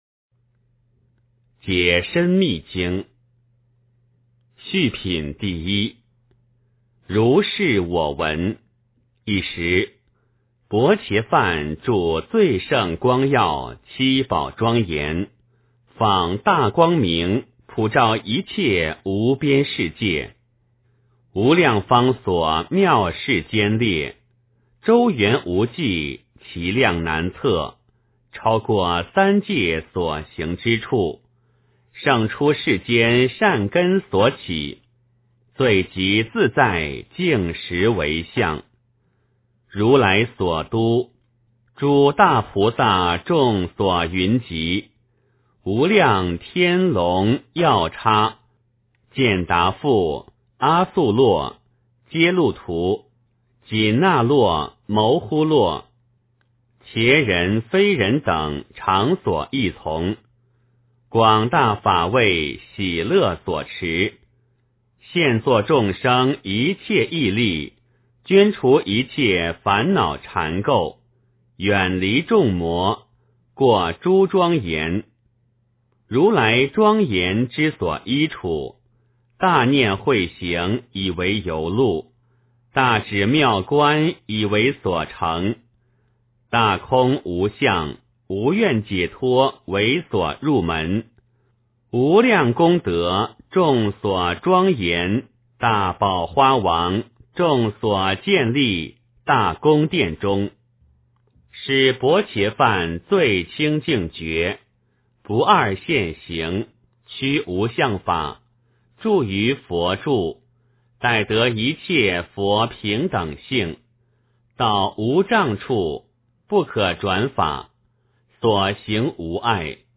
解深密经-1（念诵）